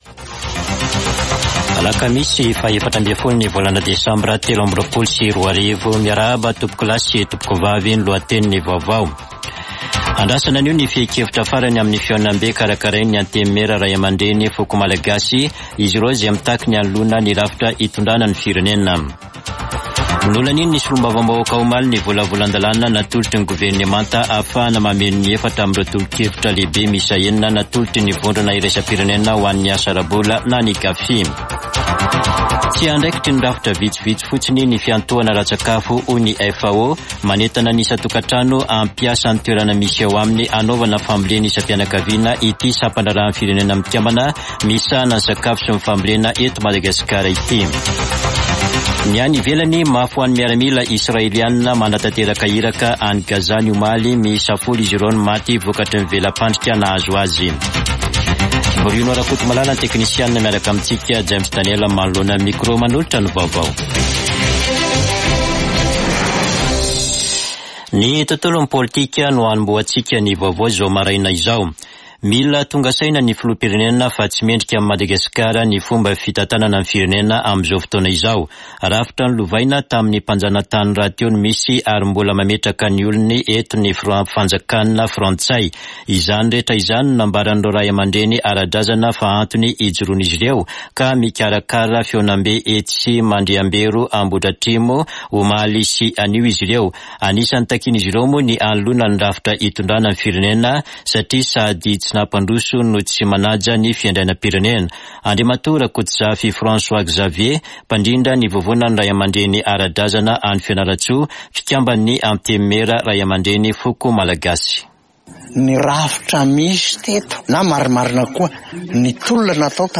[Vaovao maraina] Alakamisy 14 desambra 2023